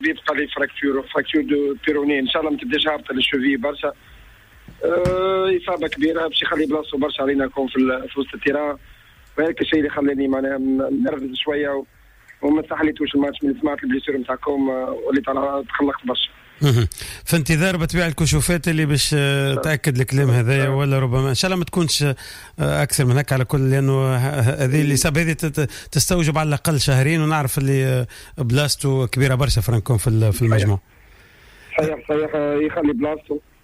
زياد الجزيري : المدير الرياضي للنجم الساحلي